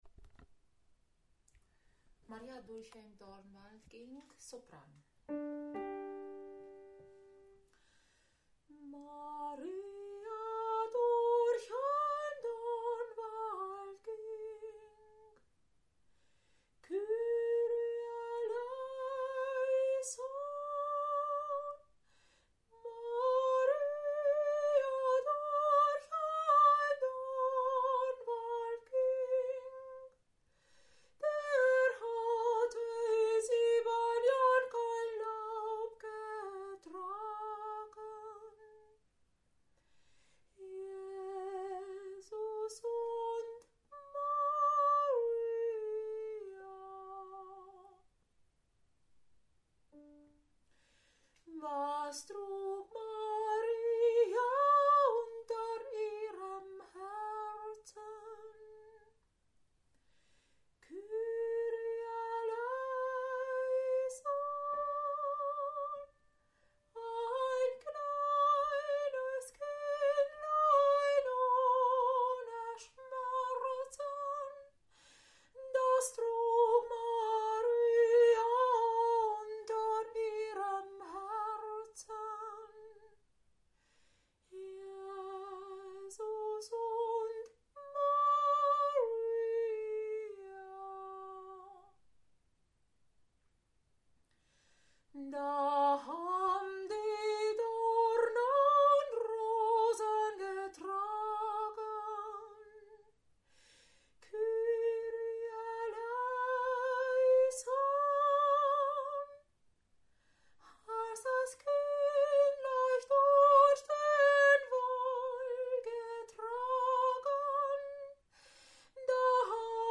Maria durch den Dornwald ging Sopran
Maria-durch-ein-Dornwald-ging-Sopran.mp3